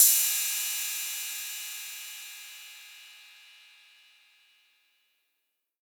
808CY_5_Tape_ST.wav